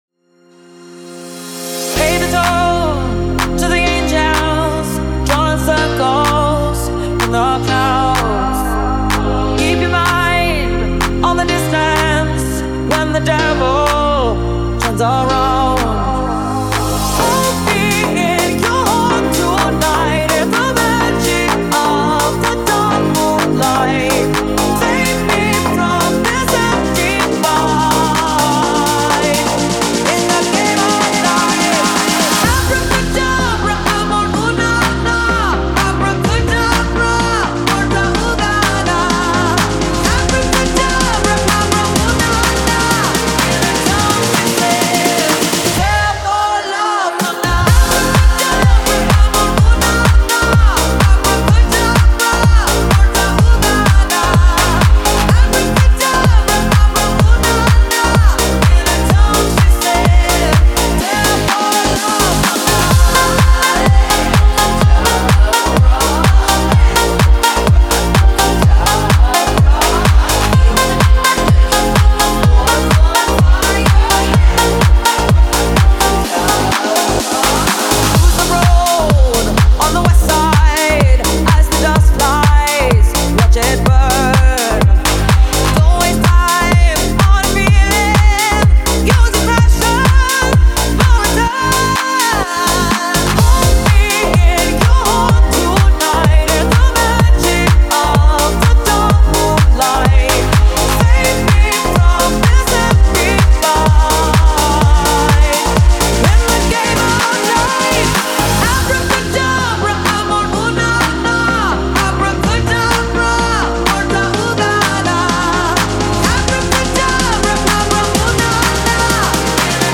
дуэт
pop